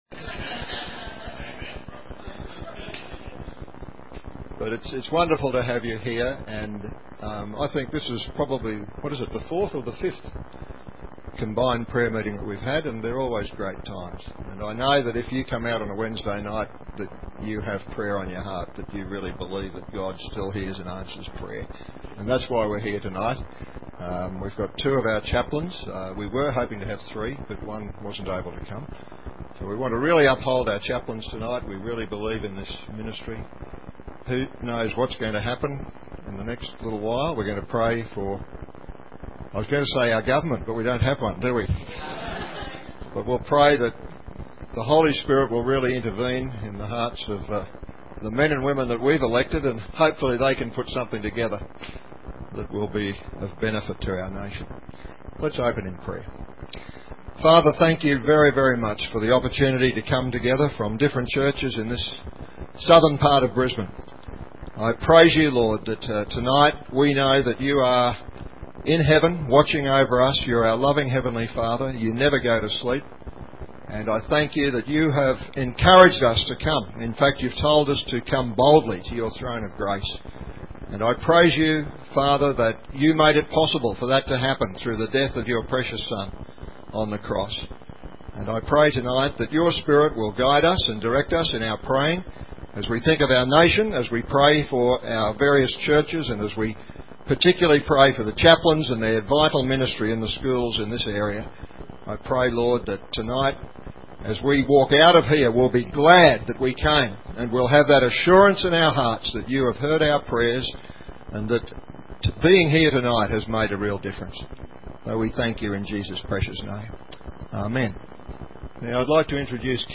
Matthew 5:13-16 Listen to the sermon here.